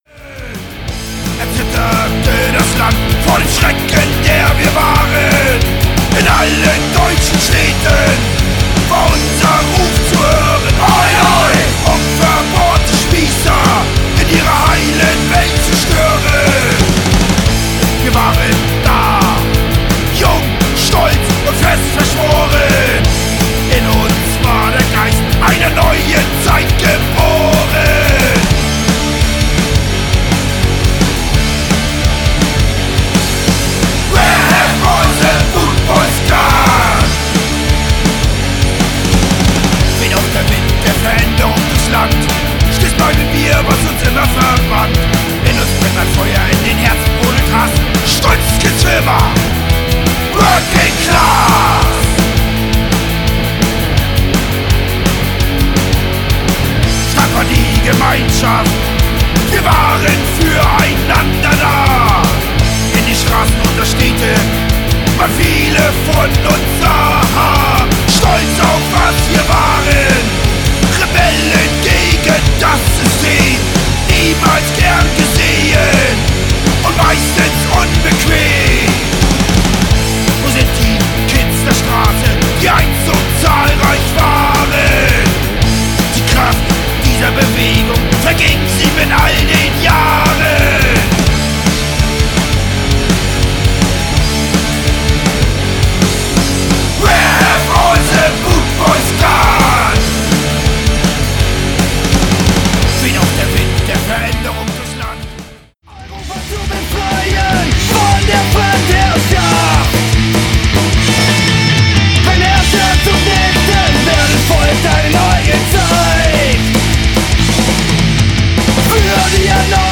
Das klingt alles sauber.